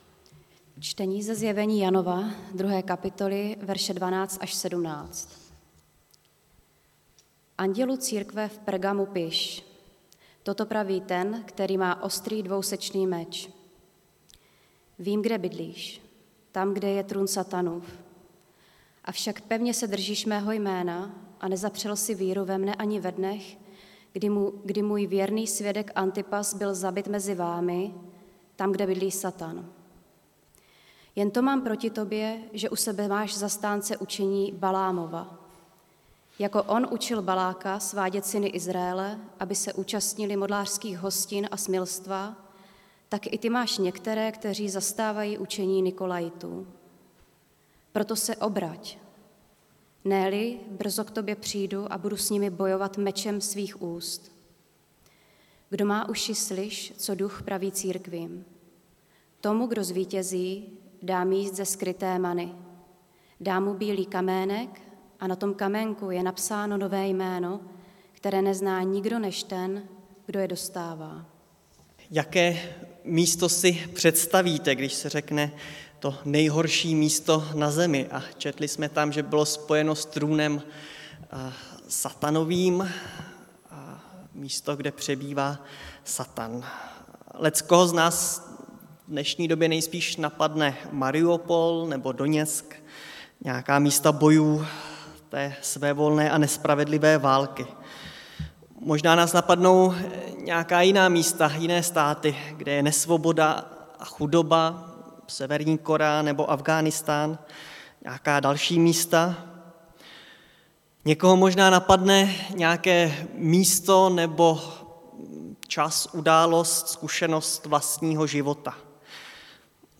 Nedělení kázání – 22.5.2022 List do Pergama